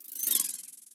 Fantasy Creatures Demo
Fairy_fly_10_pass.wav